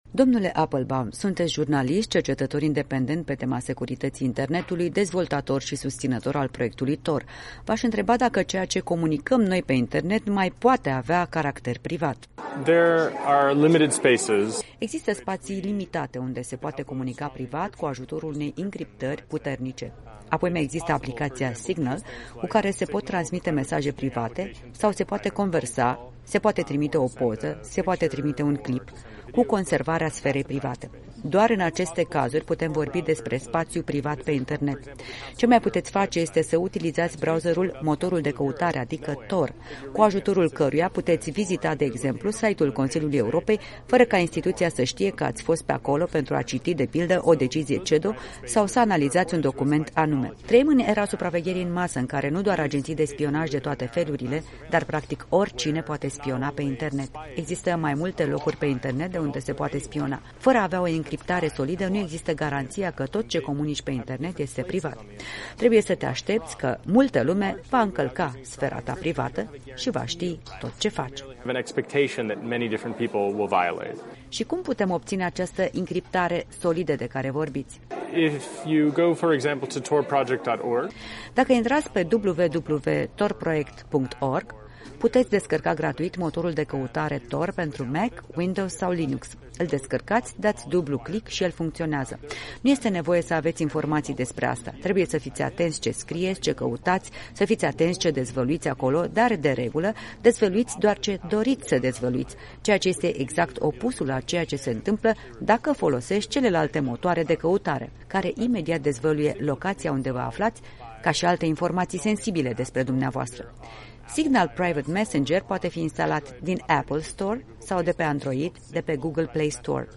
Interviul cu Jacob Appelbaum